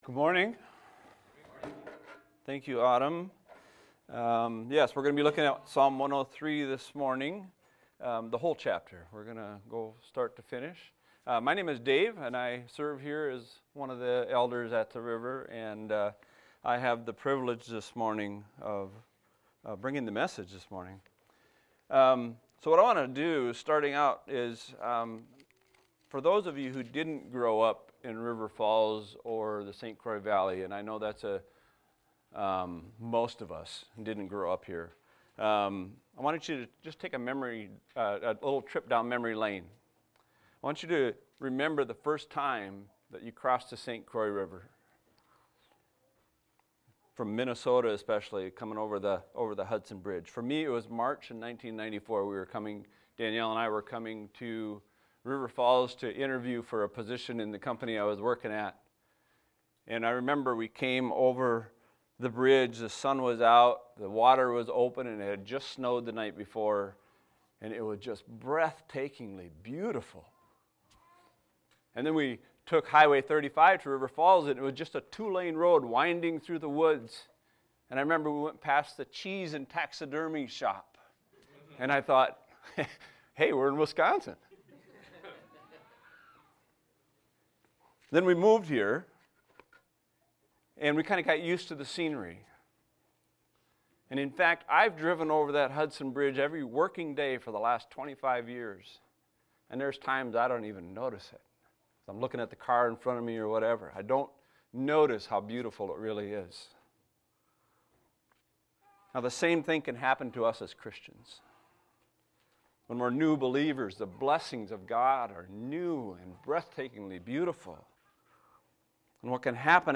A sermon on Psalm 103 titled Bless the Lord O My Soul